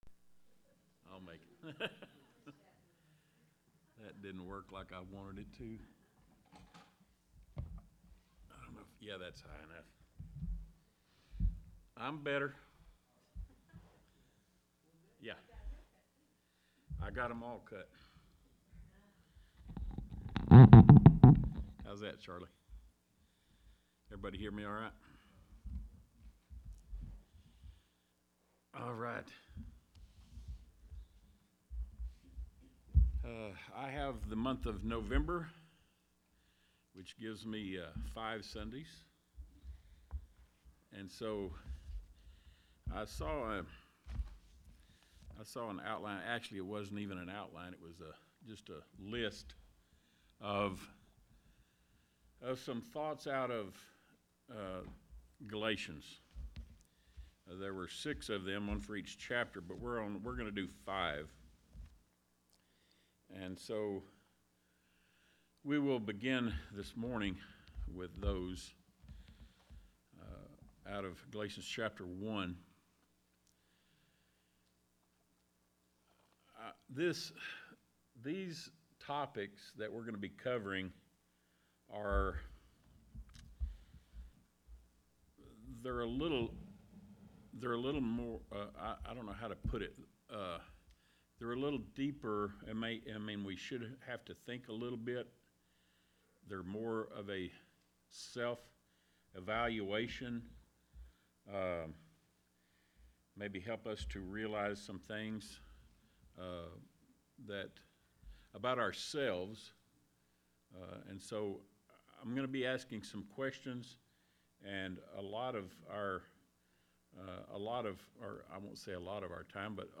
Sunday Bible Class